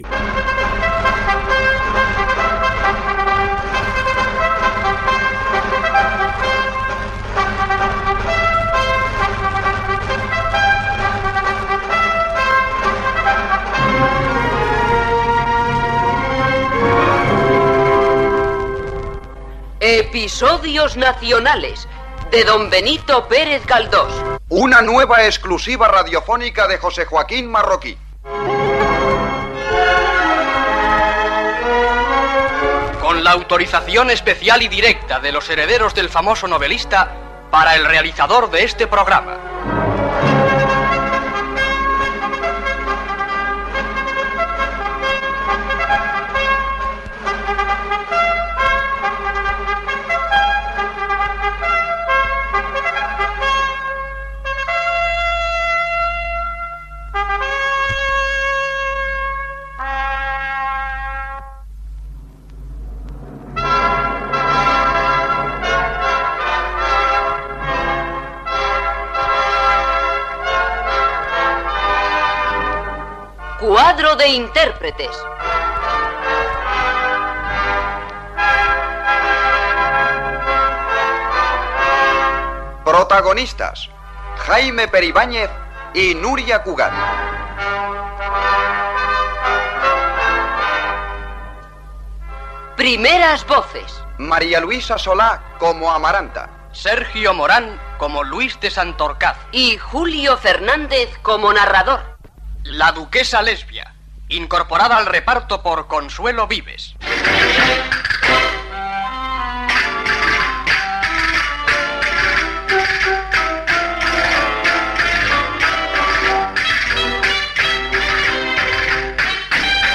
Adaptació radiofònica dels "Episodios Nacionales" de Benito Pérez Galdós.
Ficció